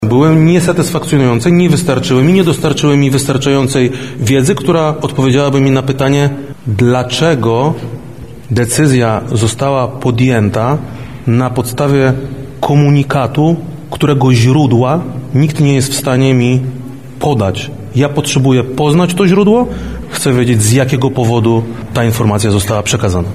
Krzysztof Komorski – wyjaśnia wojewoda lubelski, Krzysztof Komorski.